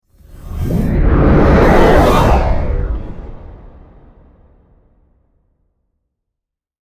Sci-Fi Energy Burst Sound Effect
Description: Sci-fi energy burst sound effect. A powerful energy burst creates dramatic movement for appearing or disappearing scenes.
This high-impact sound adds excitement and intensity to any project.
Sci-fi-energy-burst-sound-effect.mp3